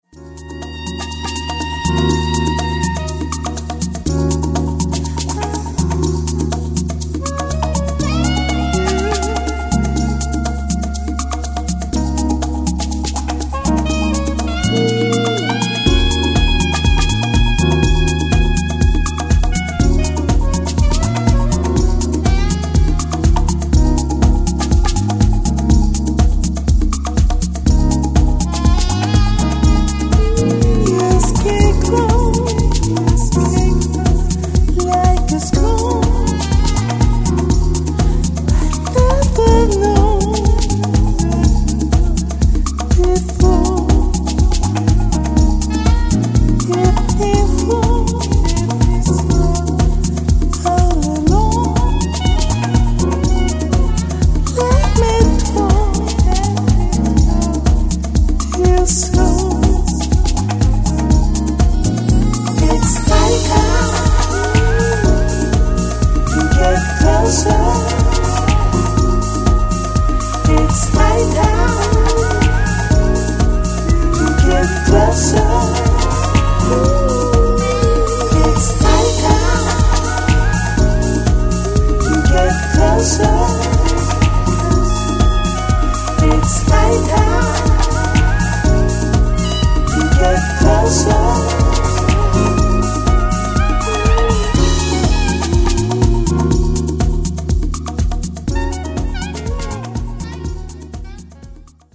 癒しのファルセット・ヴォイス。